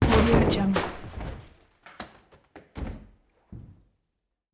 دانلود افکت صدای کوبیدن درب چوبی به هم
Sample rate 16-Bit Stereo, 44.1 kHz